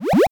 artificial computer correct ding droid gadget game interface sound effect free sound royalty free Sound Effects